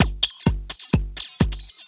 Electrohouse Loop 128 BPM (32).wav